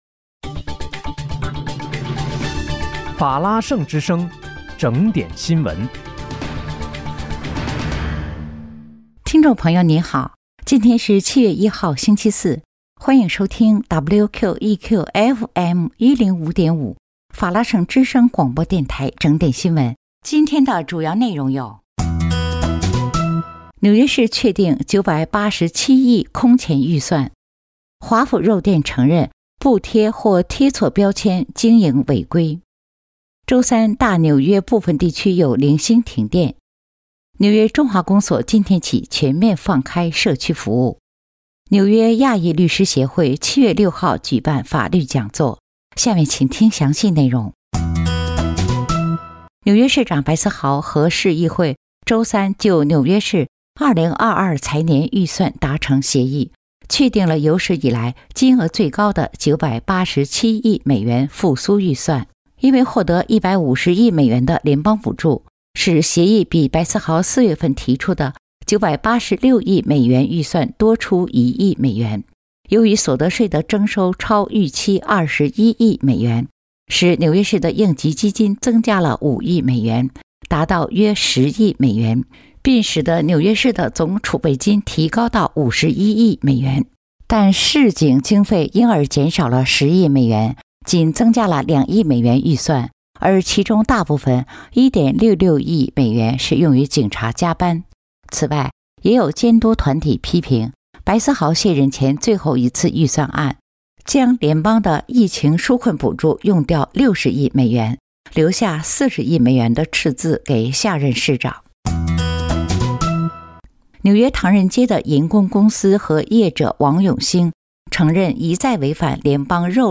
7月1日（星期四）纽约整点新闻
听众朋友您好！今天是7月1号，星期四，欢迎收听WQEQFM105.5法拉盛之声广播电台整点新闻。